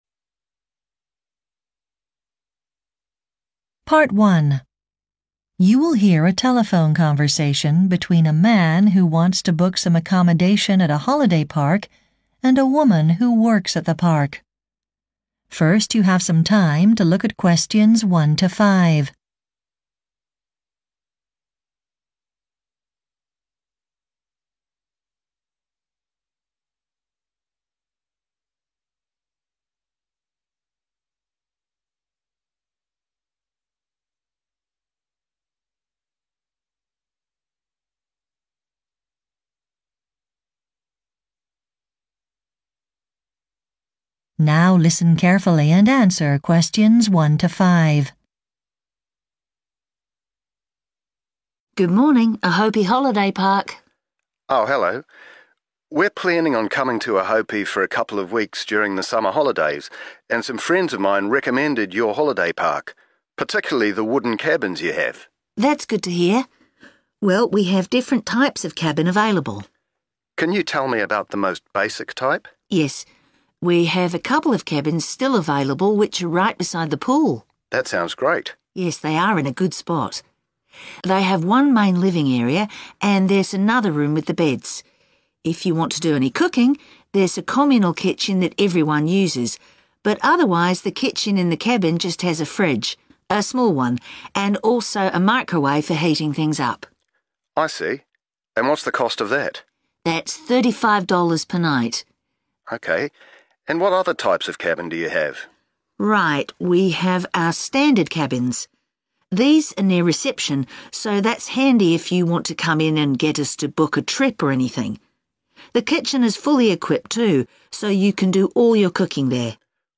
The IELTS Listening section often begins with a dialogue set in everyday contexts, such as booking accommodation or making travel arrangements.